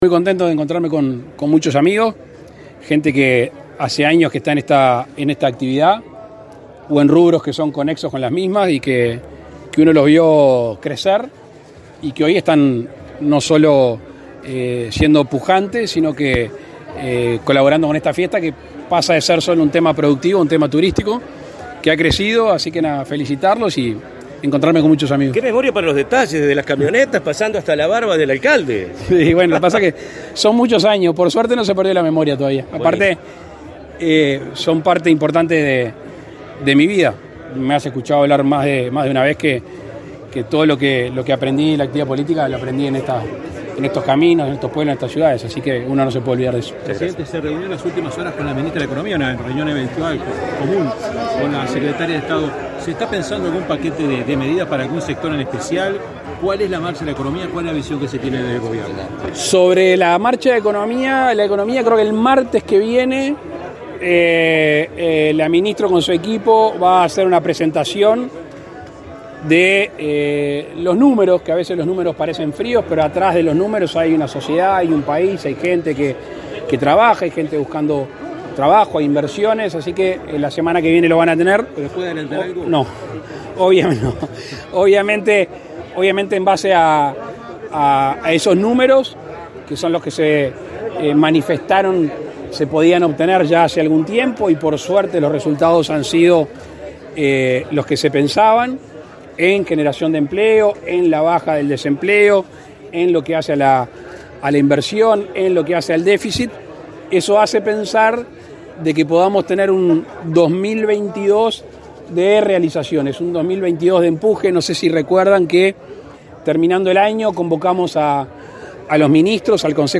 Declaraciones del presidente Lacalle Pou a la prensa
El presidente Luis Lacalle Pou encabezó la inauguración de la Expo Avícola 2022 en San Bautista, Canelones, y, luego, dialogó con la prensa.